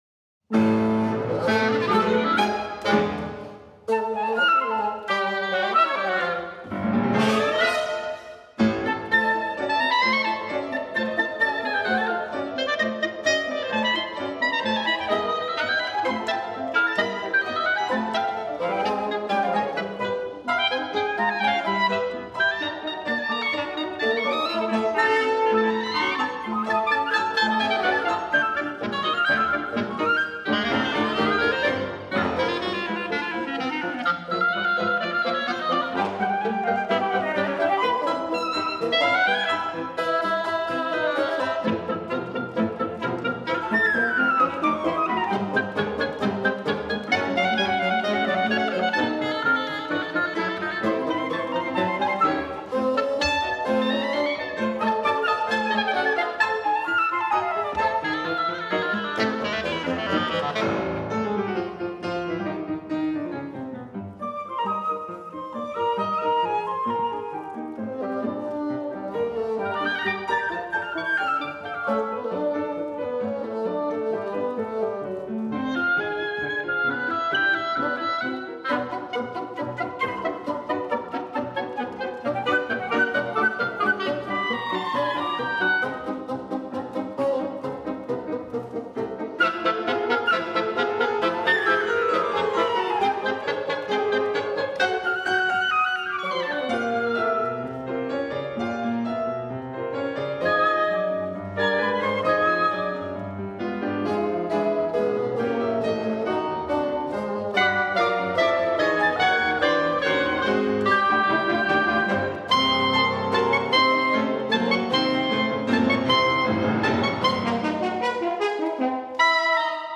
Francis Poulenc [1899-1963] Sextet for piano, flute, oboe, clarinet, bassoon and horn [1931-1939] Allegro vivace Divertissement Finale The Sextet was first heard in its original version in 1931.
Venue: St. Brendan’s Church
Instrumentation: fl, ob, cl, bn, hn, pf Instrumentation Category:Sextet
piano
flute
oboe
clarinet
horn
bassoon]) - [Wind Quintet]